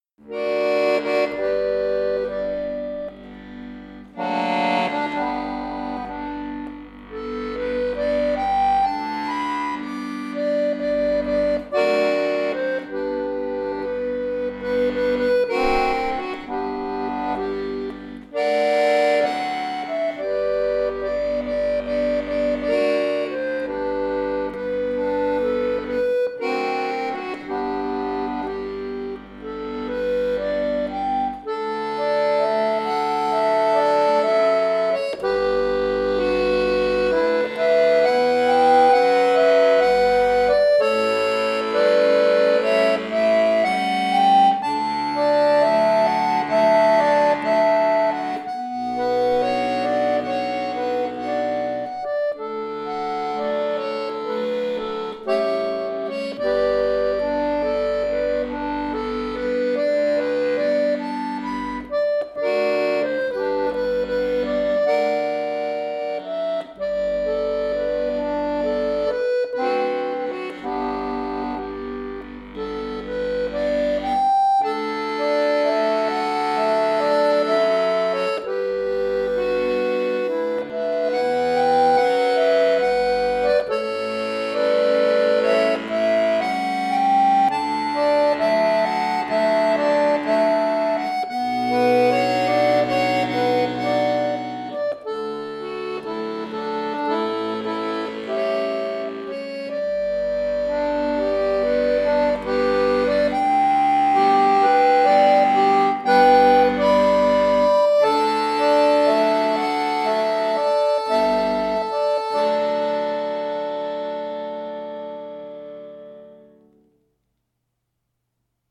arrangiert für Akkordeon solo
Oper